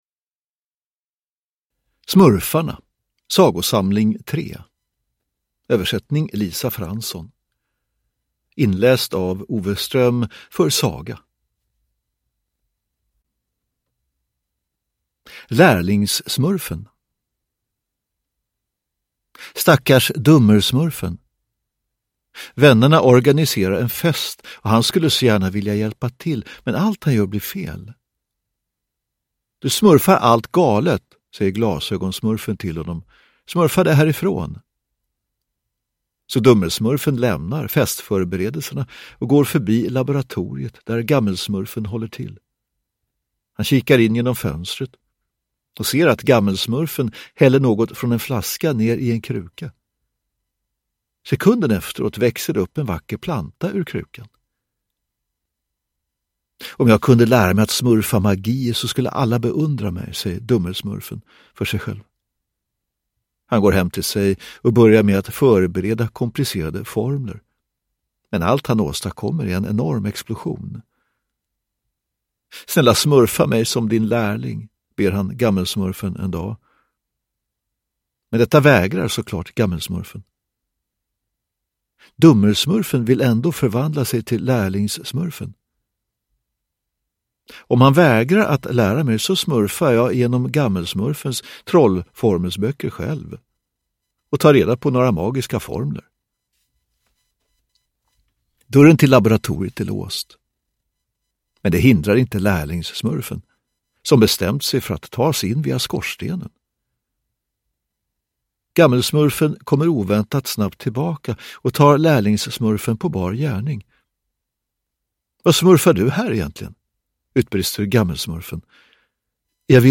Smurfarna - Sagosamling 3 (ljudbok) av Peyo